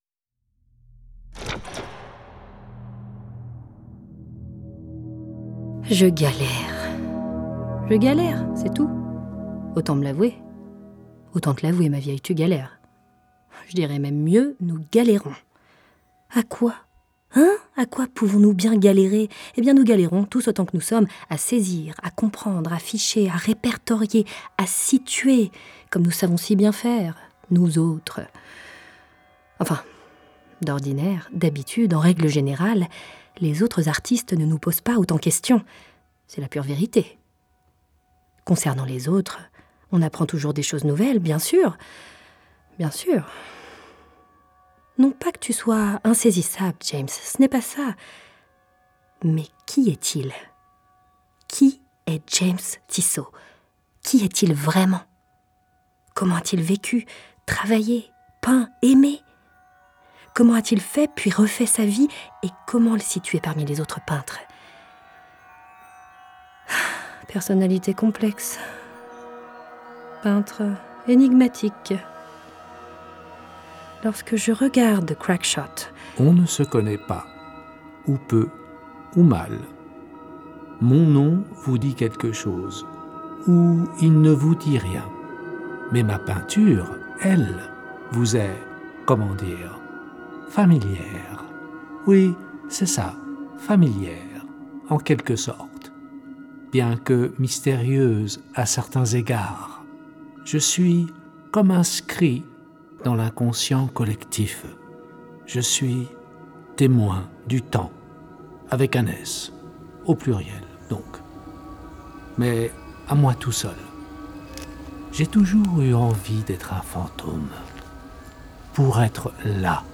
A la recherche de James Tissot, une fiction en 8 épisodes, une co-production musée d’Orsay et le Studio Radio France.